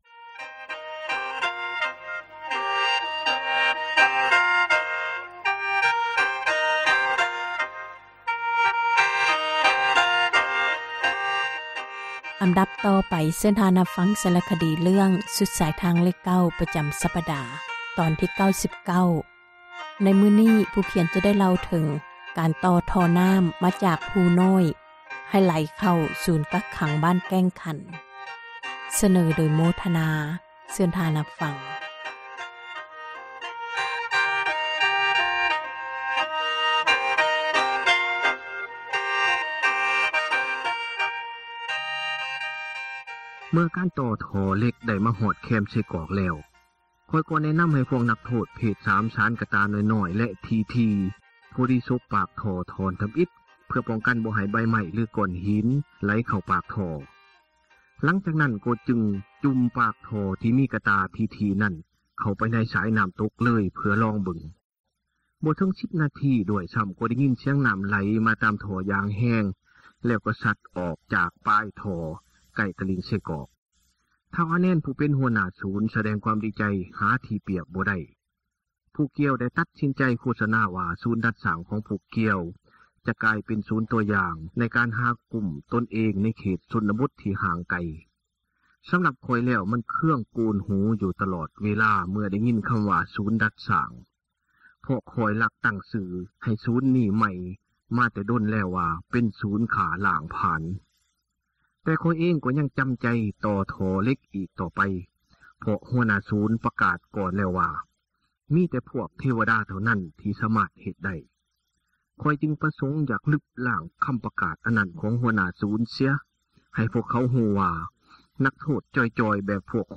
ສາຣະຄະດີ ສຸດສາຍທາງເລຂ 9 ຕອນທີ 99